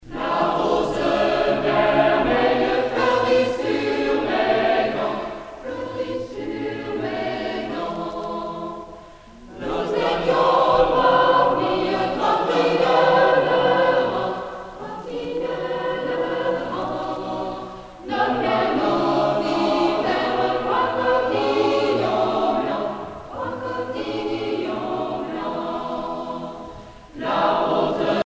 Genre laisse
Catégorie Pièce musicale éditée